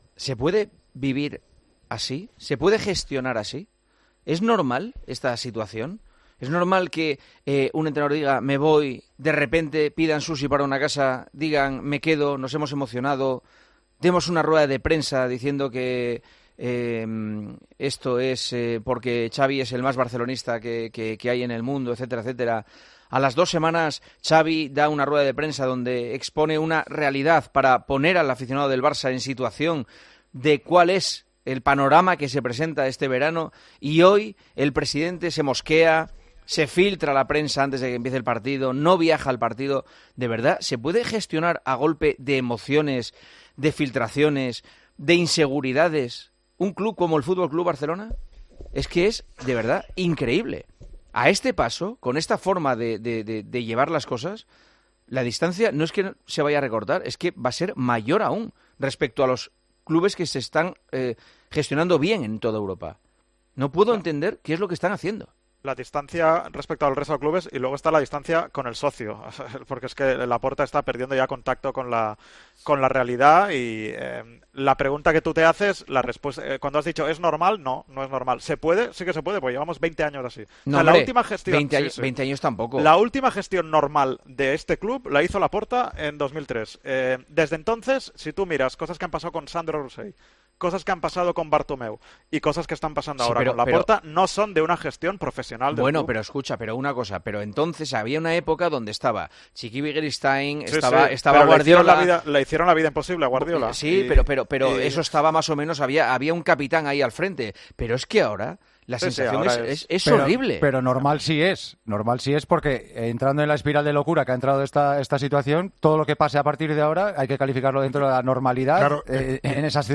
El director de El Partidazo de COPE reflexionó sobre las tensiones constantes en las que vive el conjunto azulgrana, después de que el presidente no viajar a Almería.
Juanma Castaño empezó El Partidazo de COPE exponiendo una reflexión sobre la gestión que está realizando Joan Laporta al frente del Barcelona.